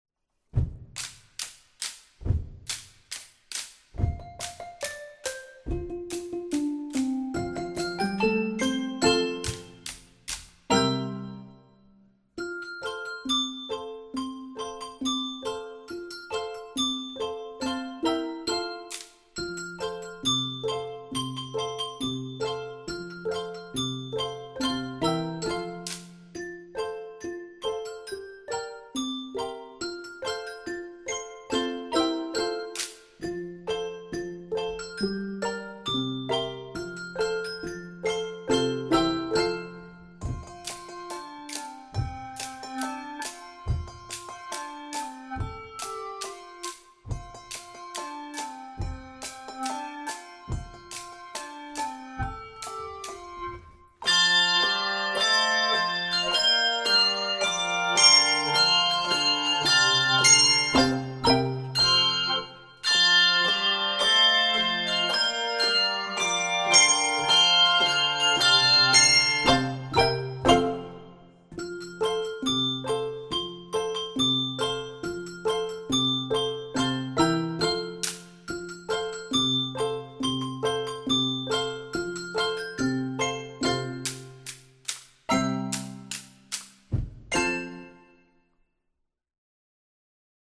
Key of F Major.